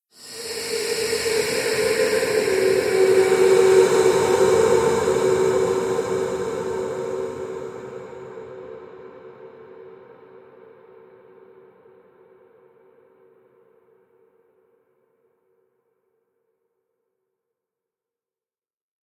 Звук Призрака Женского Ужаса
zvuk_prizraka_zhenskogo_uzhasa_uj3.mp3